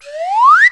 Swannee Whistle sound
Going up.
swannee.wav